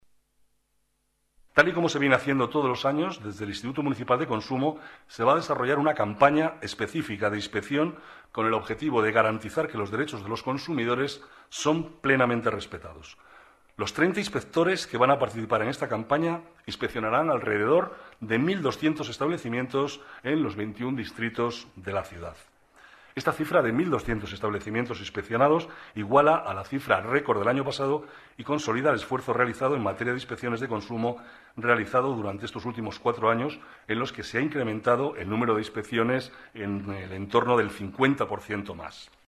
Nueva ventana:Declaraciones del vicealcalde, Manuel Cobo: Control de rebajas de verano 2009